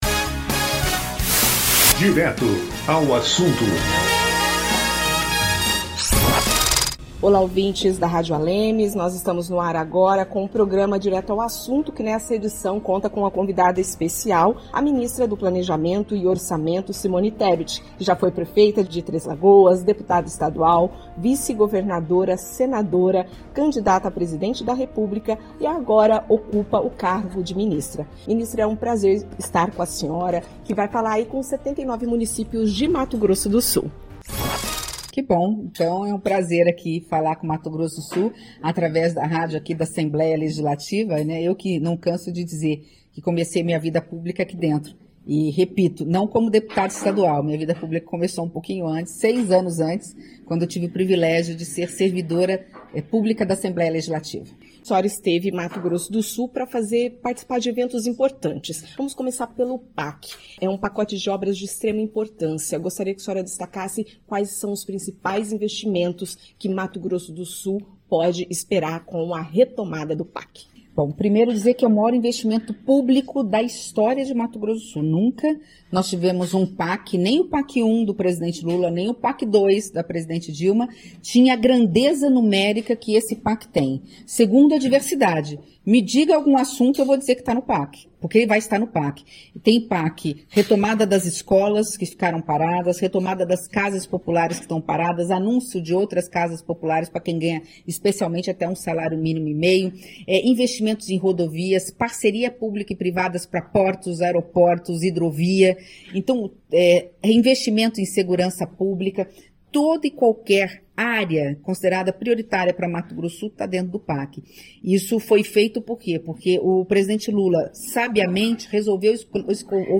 Direto ao Assunto traz entrevista exclusiva com ministra Simone Tebet